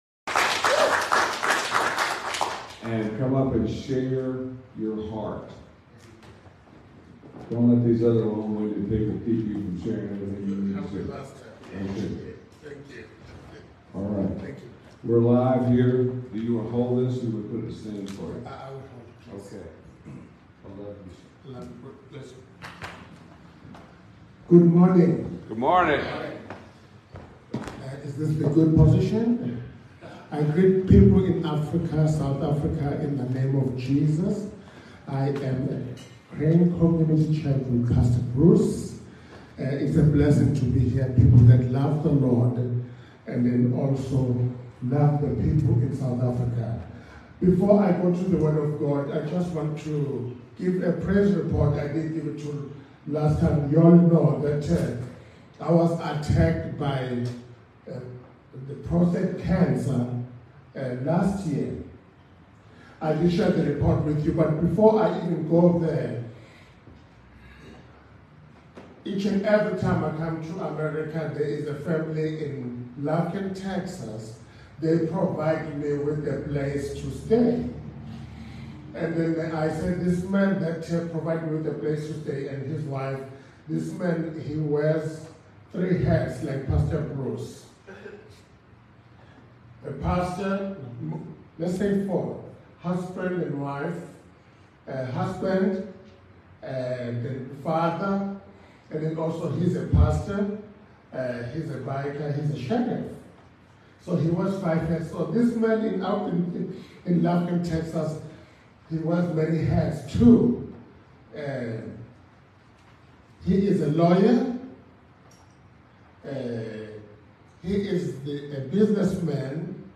Sunday Sermon 8-16-23